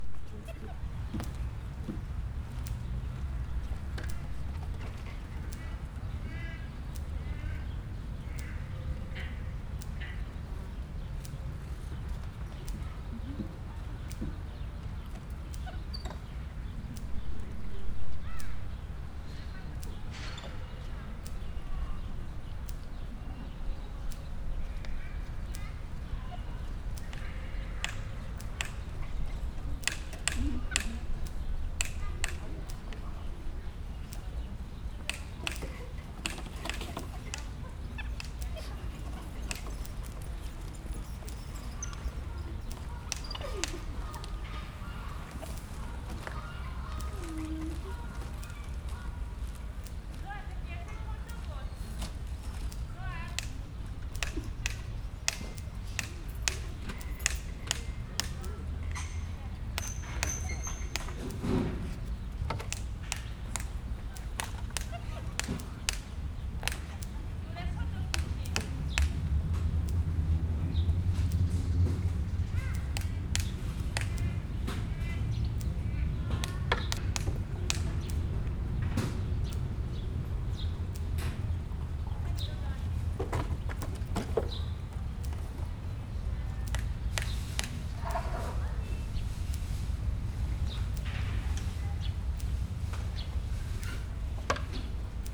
Directory Listing of /_MP3/allathangok/debrecenizoo2019_professzionalis/kapucinus_majom/